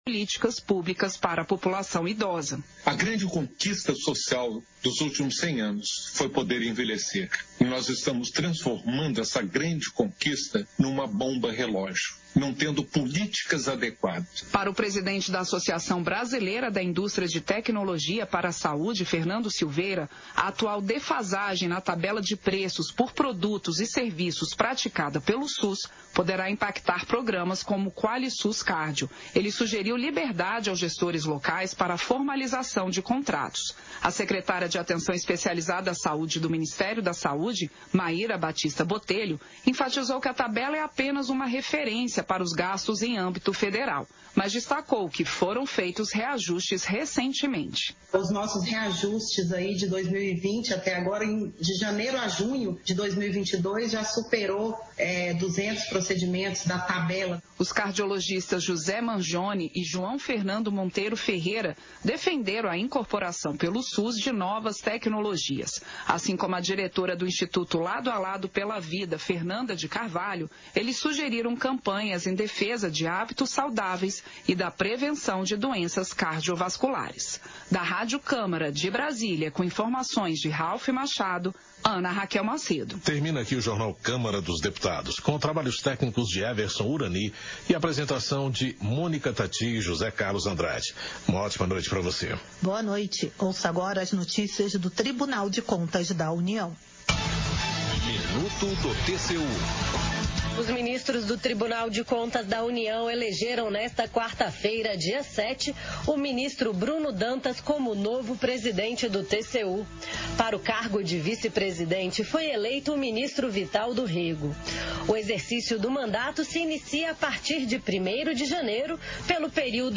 Sessão Ordinária 41/2022